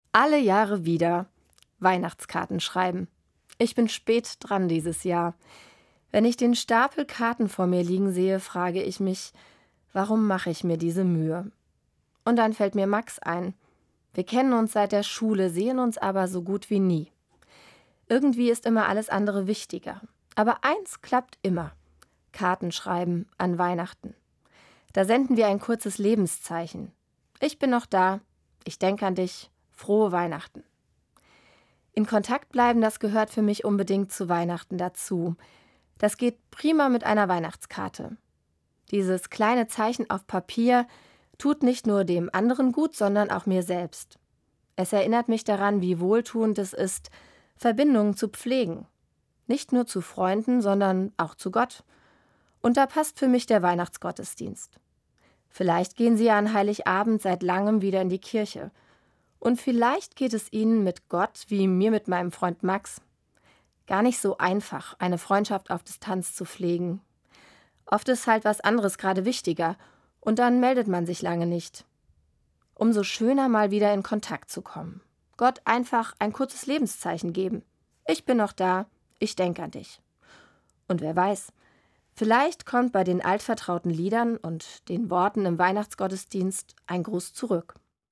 Evangelische Pfarrerin, Herborn